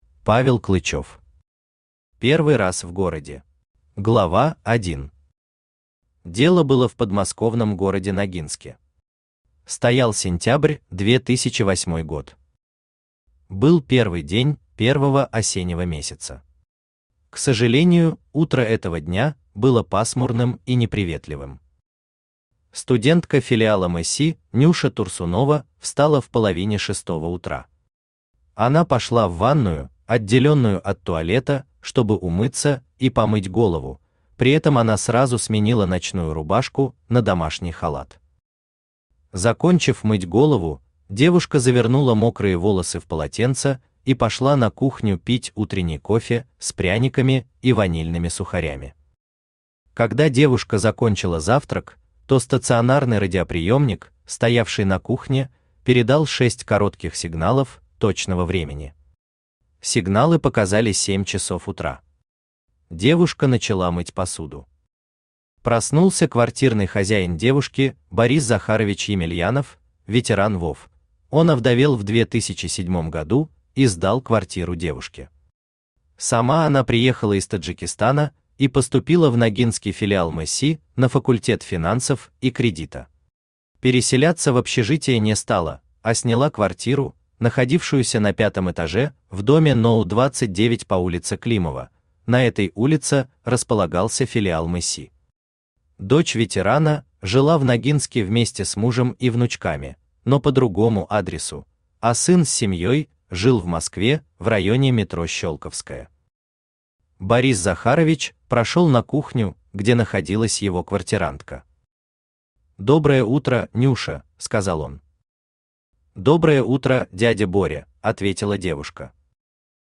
Аудиокнига Первый раз в городе | Библиотека аудиокниг
Aудиокнига Первый раз в городе Автор Павел Геннадьевич Клычов Читает аудиокнигу Авточтец ЛитРес.